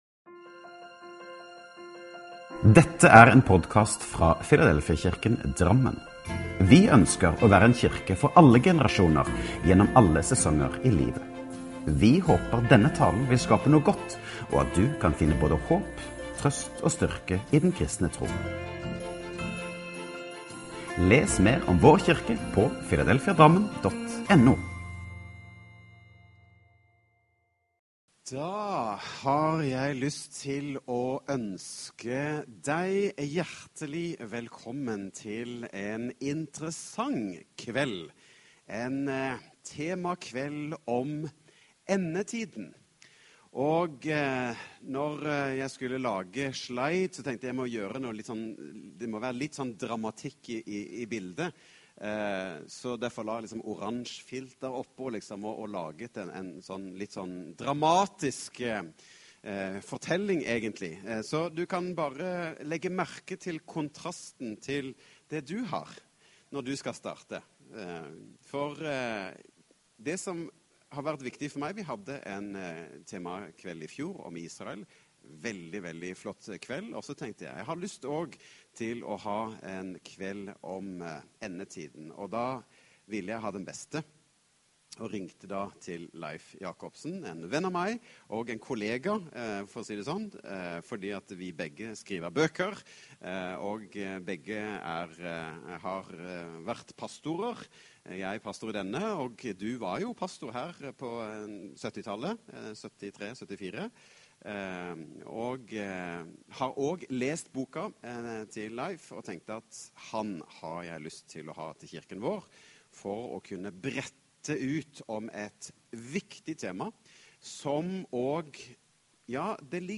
Last ned talen til egen maskin eller spill den av direkte: Taler